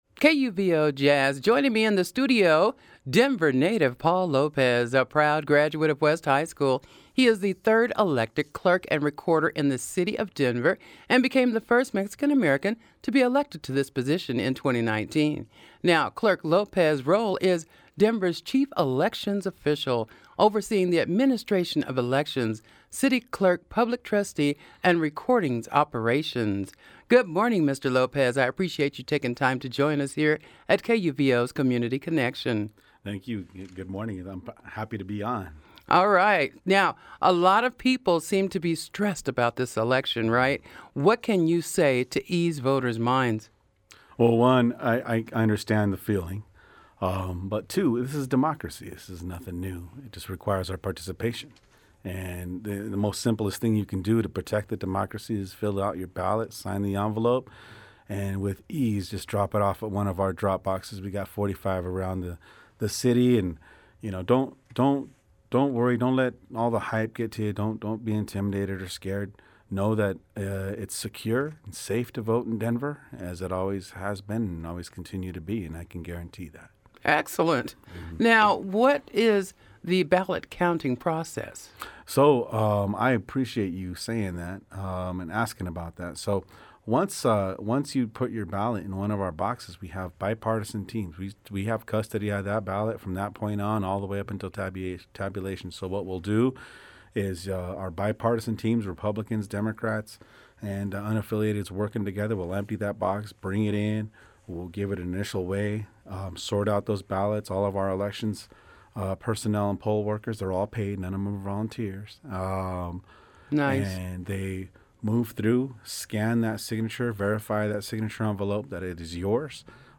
KUVO JAZZ Interview with Denver Clerk and Recorder Paul Lopez | KUVO Jazz